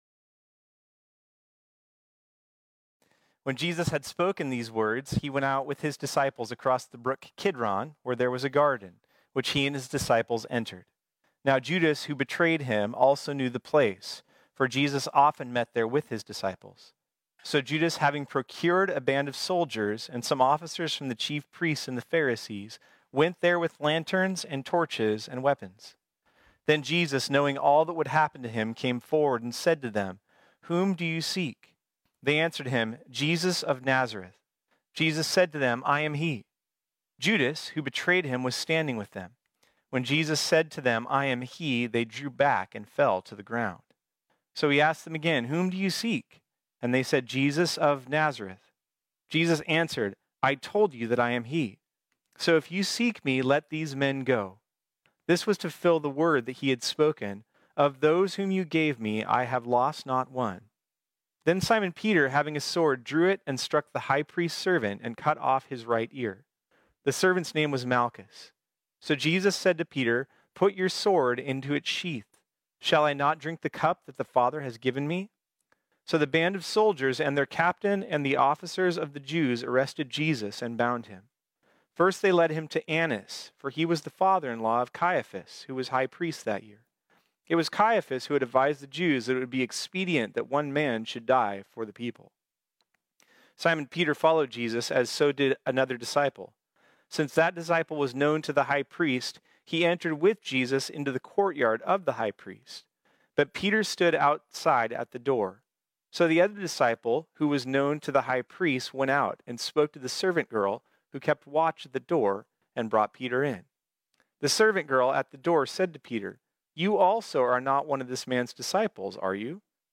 This sermon was originally preached on Sunday, July 12, 2020.